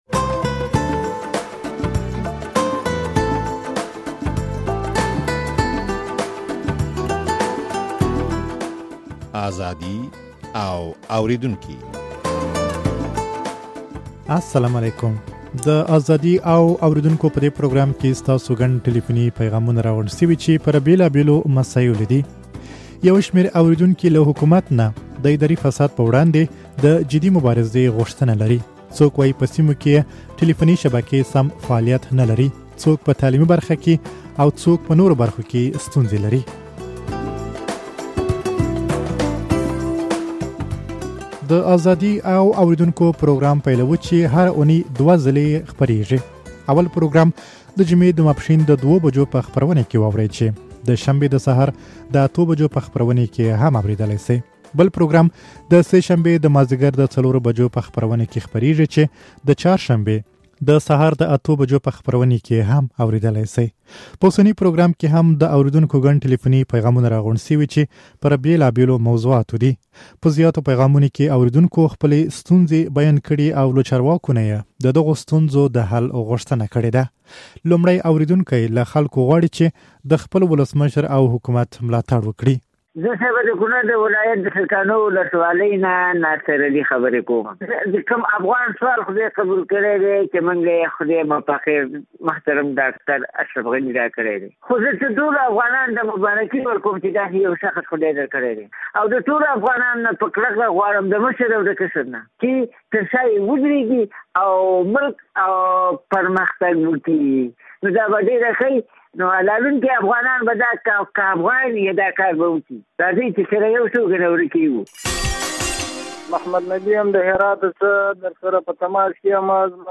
د ازادي او اورېدونکو په دې پروګرام کې ستاسو ګڼ ټليفوني پيغامونه راغونډ شوي چې پر بېلابېلو مسايلو دي.